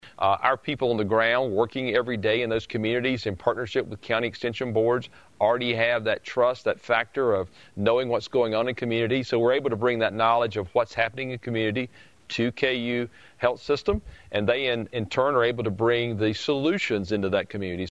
The two organizations made the announcement during a joint news conference.